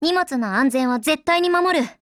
贡献 ） 协议：Copyright，其他分类： 分类:少女前线:SP9 、 分类:语音 您不可以覆盖此文件。